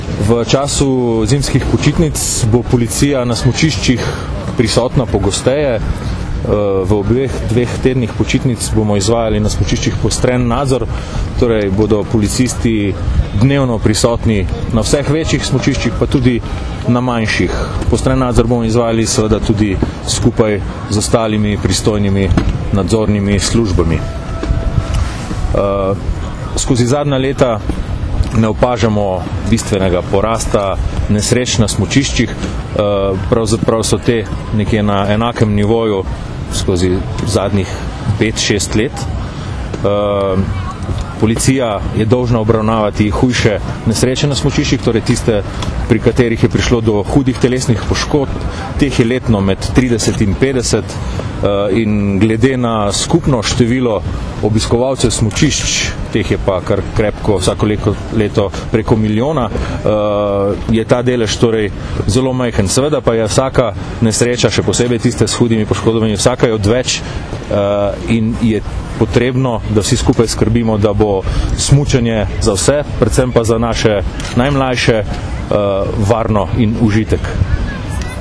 Na današnji novinarski konferenci, ki je potekala na Krvavcu, smo opozorili, na kaj vse morajo biti smučarji pozorni, in kako bomo za večjo varnost skrbele pristojne službe.